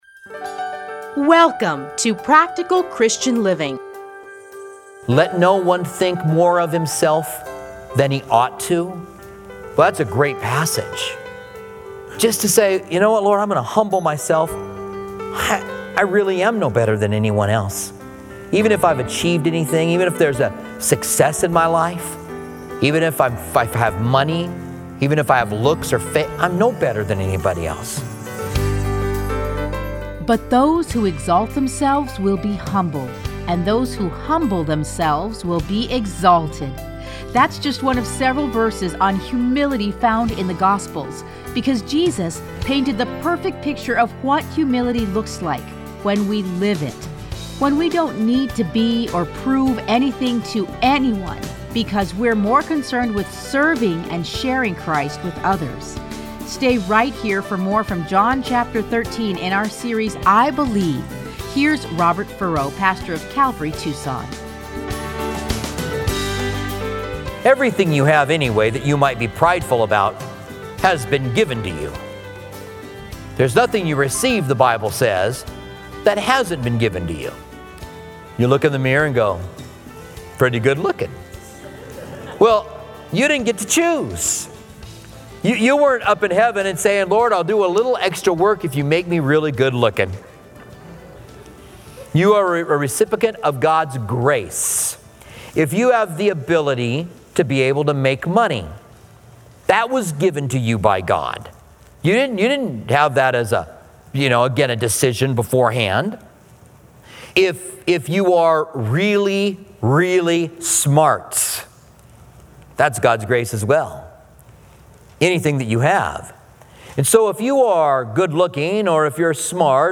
Listen to a teaching from John 13:1-17.